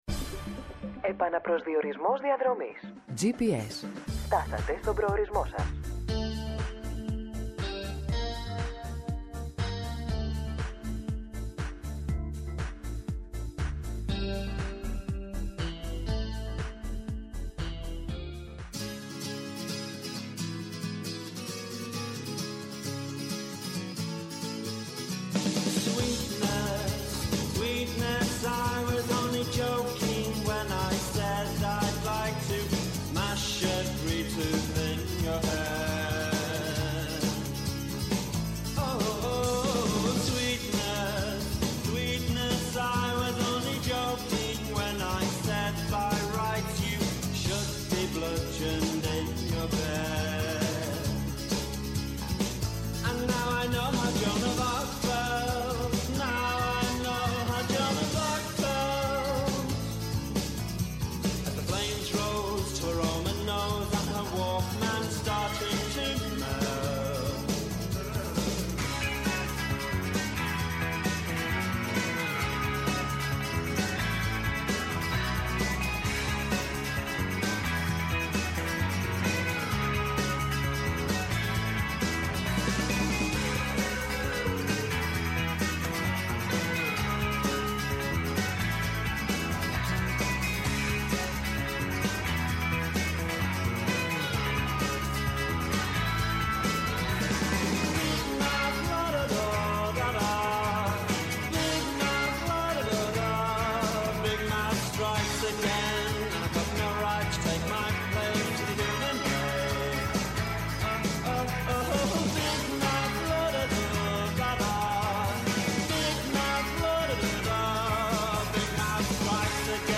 -Ο Νίκος Ταχιάος, υφυπουργός Υποδομών και Μεταφορών, αρμόδιος για θέματα υποδομών
-O Αλκιβιάδης Στεφανής, στρατηγός εν αποστρατεία και πρώην υφυπουργός Εθνικής Άμυνας